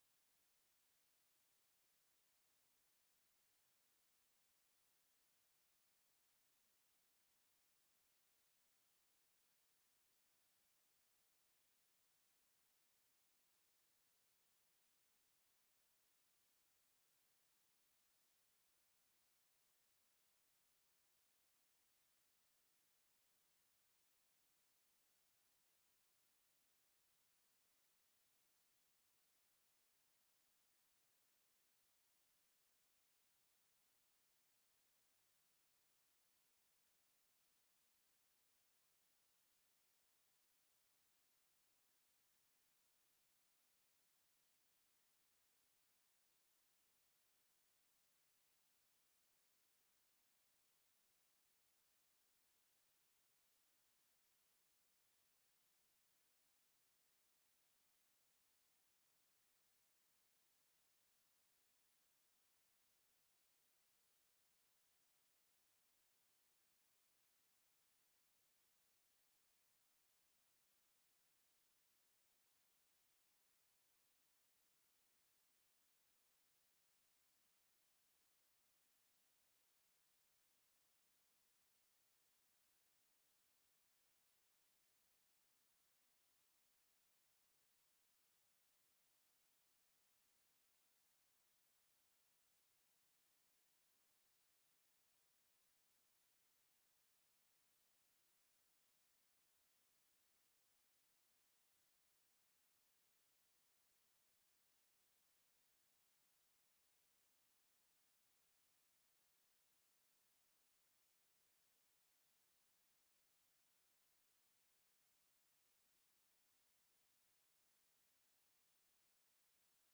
Live from the Village of Philmont: Village Board Meeting (Audio)